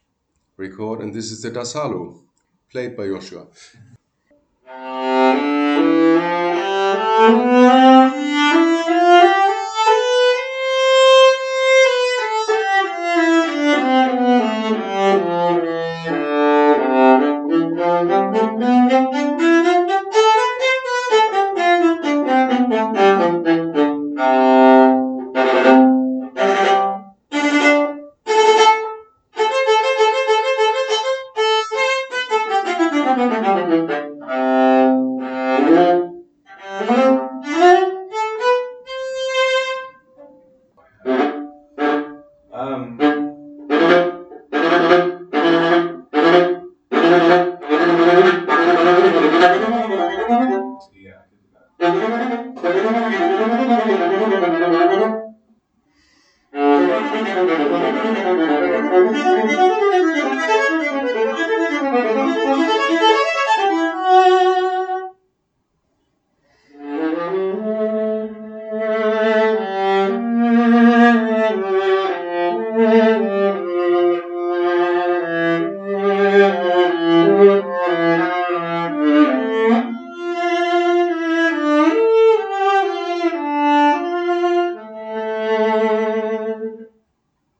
Viola after Gasparo da Salo, Brescia 424mm - 16 1/2''
The resulting sound is dark, well balanced, even and with a strong projection.